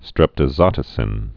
(strĕptə-zŏtə-sĭn)